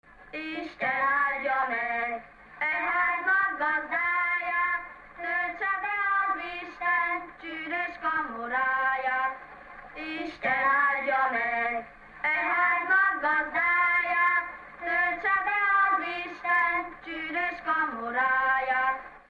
Alföld - Pest-Pilis-Solt-Kiskun vm. - Felsőerek (Szakmár)
ének
Stílus: 7. Régies kisambitusú dallamok